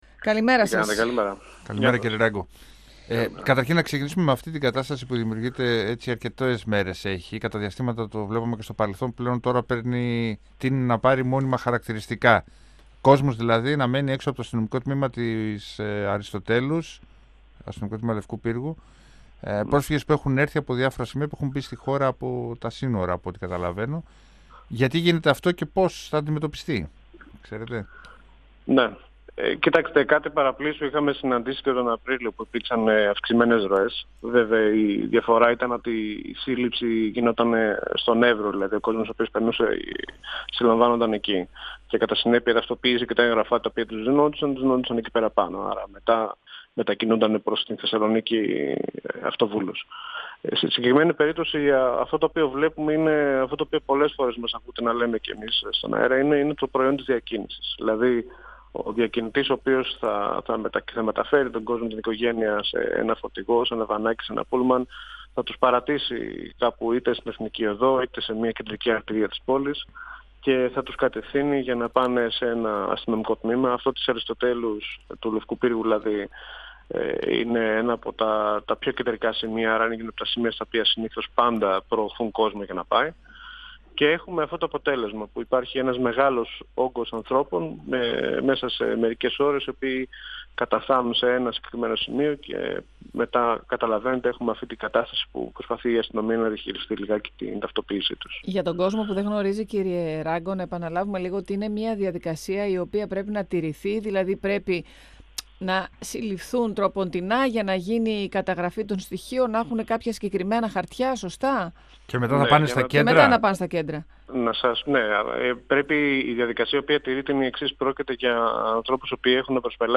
Οι συνεχείς αφίξεις δυσχεραίνουν τις διαδικασίες για την καταγραφή των μεταναστών, επισήμανε ο περιφερειακός συντονιστής Βόρειας Ελλάδας και Ηπείρου του υπουργείου Μεταναστευτικής Πολιτικής Νίκος Ράγκος, μιλώντας στον 102FM του Ραδιοφωνικού Σταθμού Μακεδονίας της ΕΡΤ3, αναφερόμενος στην κατάσταση που έχει δημιουργηθεί στην περιοχή της Αριστοτέλους, στη Θεσσαλονίκη.
Συνεντεύξεις